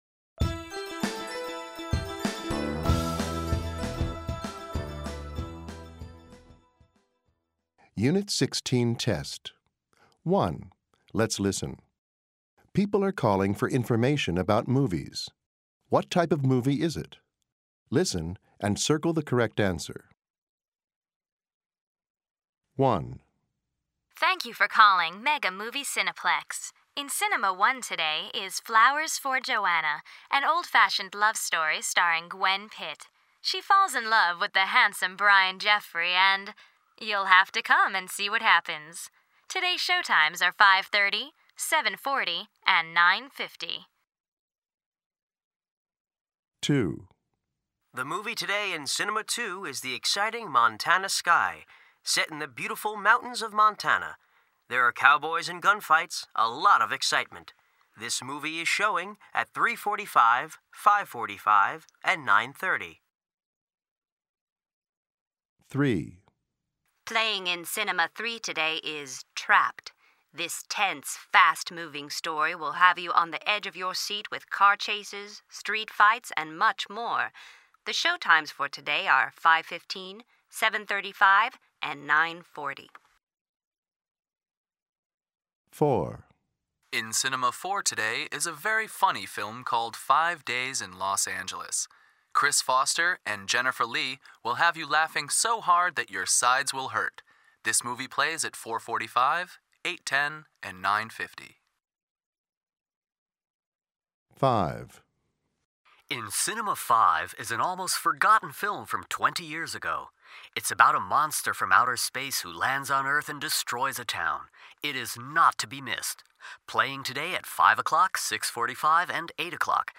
People are calling for information about movies.